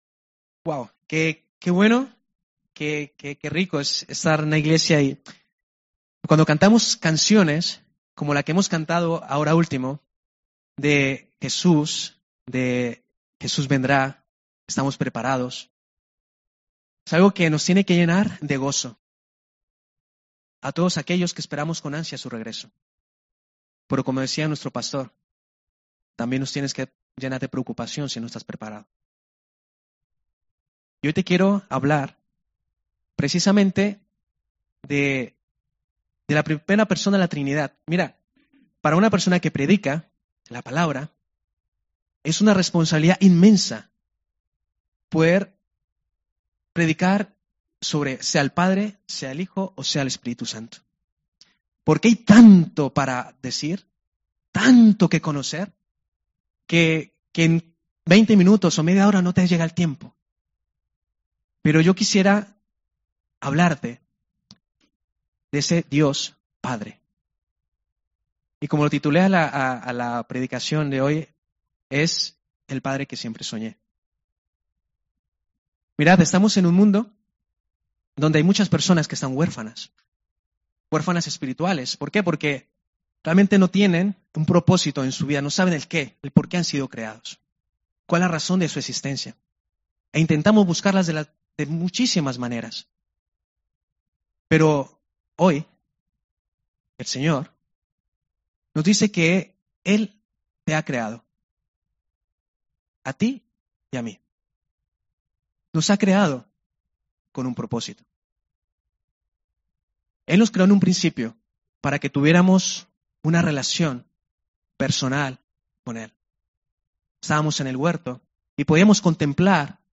Predicacions